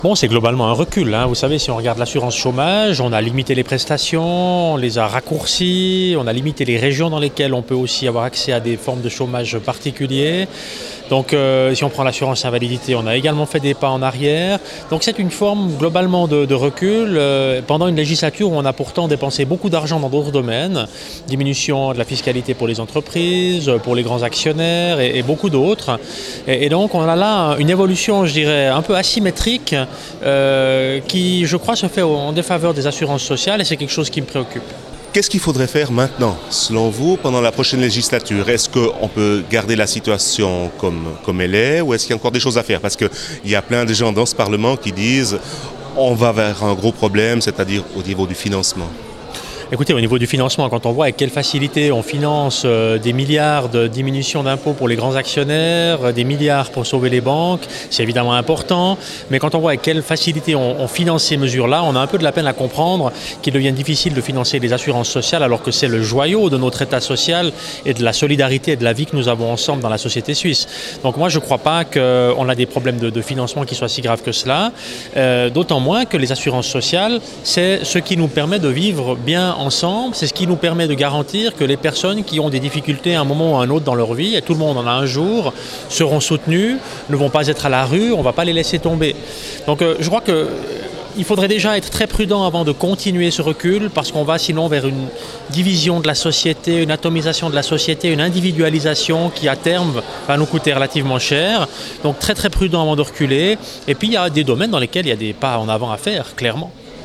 Alain Berset, sénateur socialiste